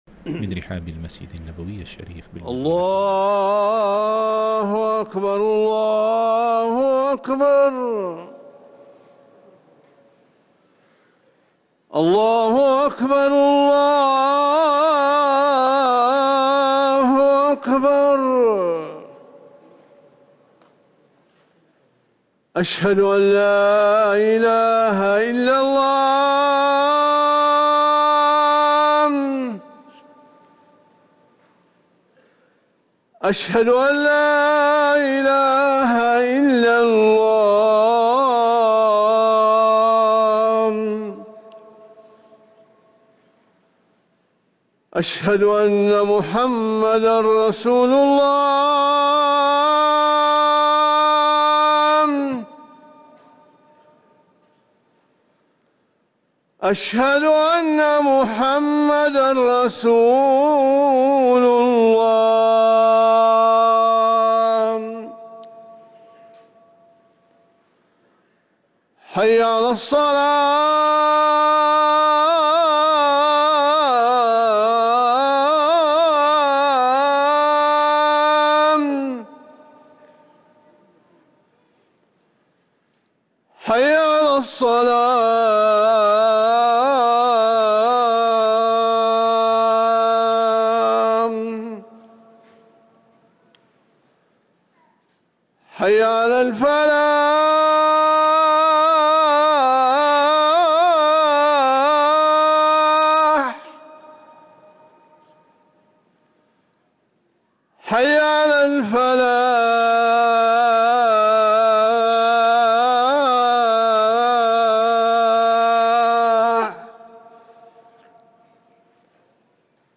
اذان المغرب